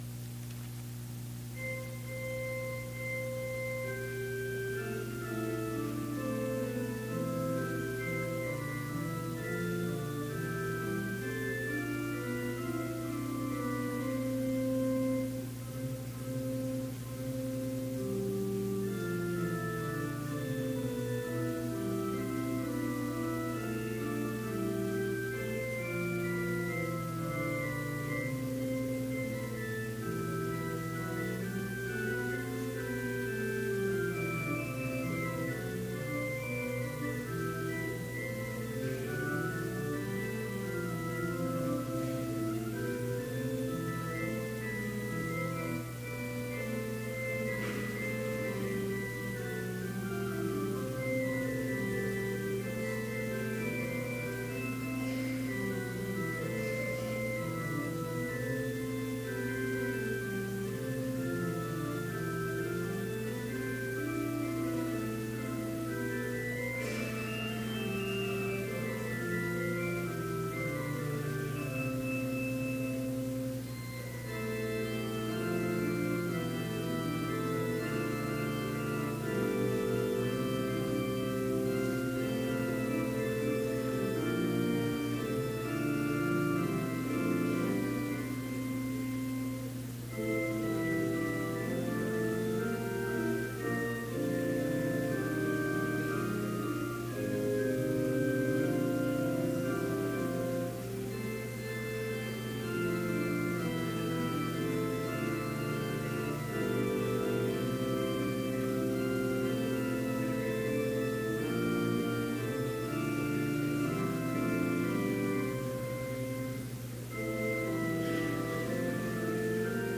BLC Trinity Chapel, Mankato, Minnesota
Complete service audio for Chapel - October 31, 2018